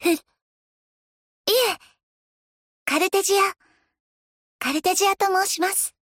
カルテジアの声来たよ🥰
コレほんまに田村ゆかりか？🌚